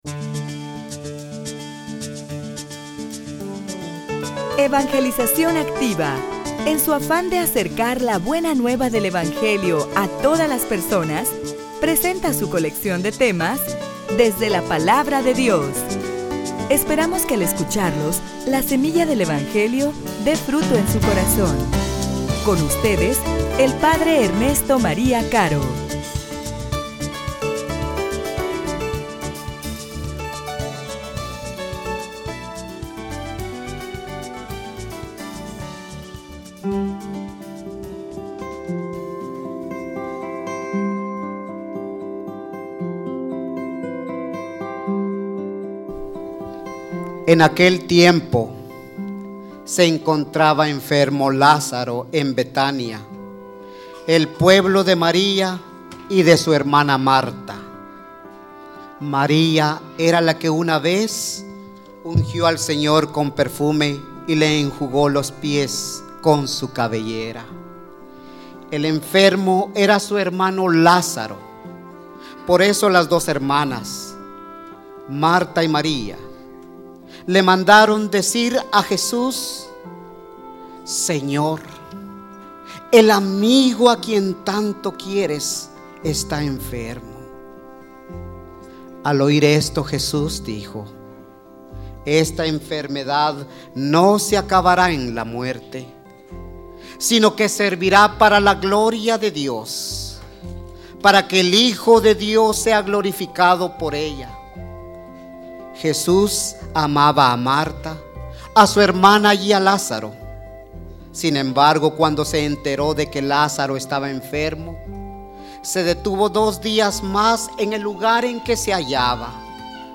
homilia_La_Palabra_que_da_vida.mp3